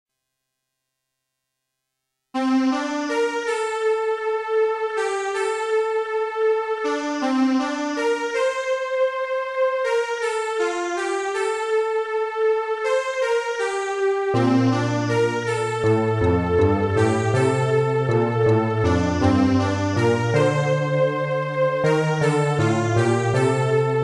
It sounds like it was made on several childrens electronic organs.
The track lacks bass, sound quality, and a couple of other things.
urgh...makes me to think techno church...
It sounds like a MIDI.
It's too elektronical...